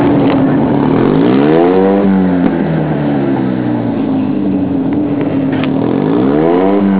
早起きしてサーキットに来てみると、すでにピットでは出走準備がすすんでいました。
一際目立つ蛍光イエローのマシンは、かの有名な「ゾルダー・ウィナー」そのものです。（ゾルダー・ウィナーの音は